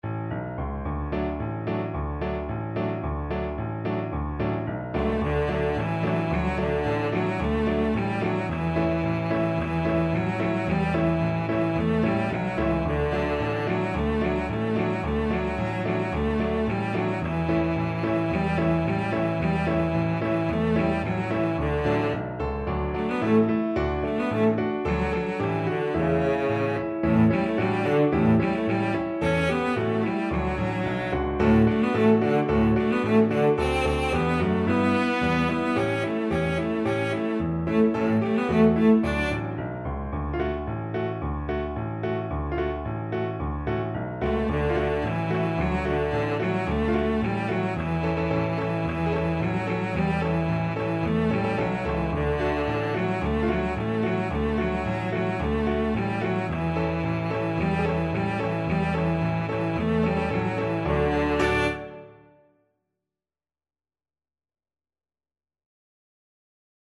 Cello version
Traditional Music of unknown author.
4/4 (View more 4/4 Music)
Playfully =c.110
Classical (View more Classical Cello Music)